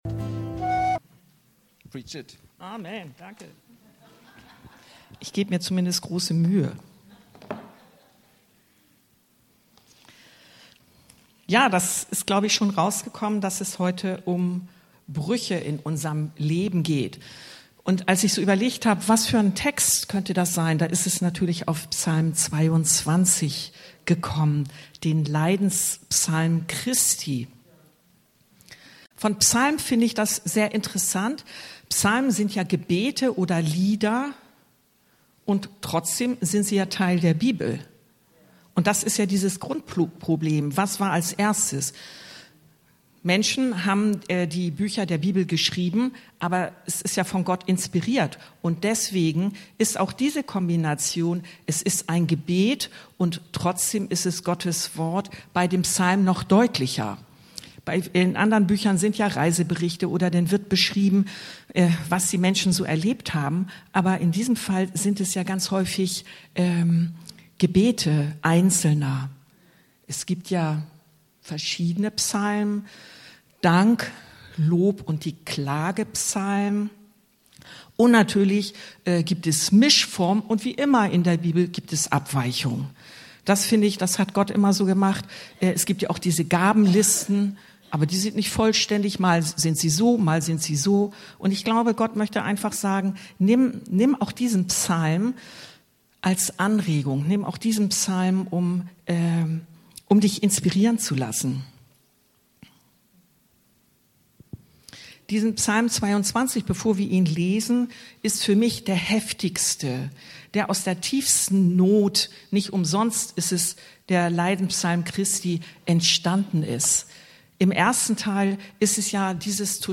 Brüche in unserem Leben, Psalm 22 ~ Anskar-Kirche Hamburg- Predigten Podcast